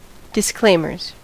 Ääntäminen
Ääntäminen US Tuntematon aksentti: IPA : /dɪsˈkleɪm.ə(r)z/ Haettu sana löytyi näillä lähdekielillä: englanti Käännöksiä ei löytynyt valitulle kohdekielelle.